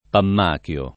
[ pamm # k L o ]